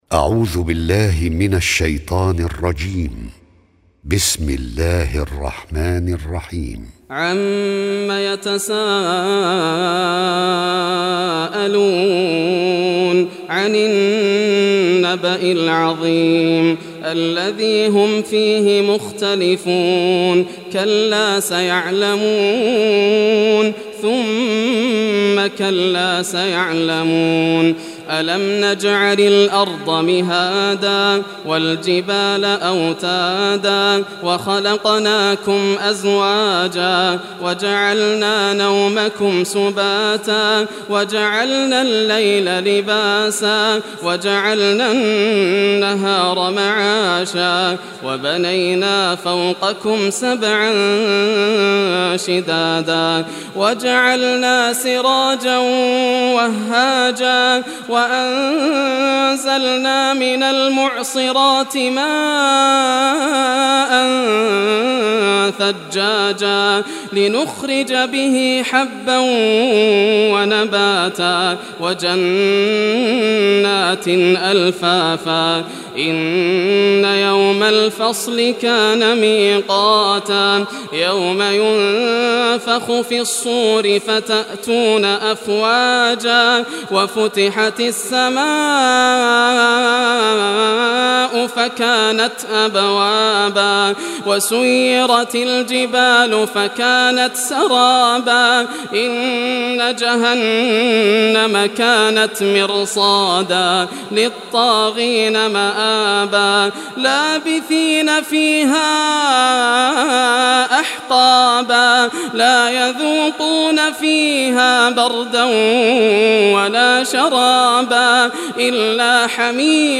Surah An-Naba Recitation by Yasser al Dosari
Surah An-Naba, listen or play online mp3 tilawat / recitation in Arabic in the beautiful voice of Sheikh Yasser al Dosari.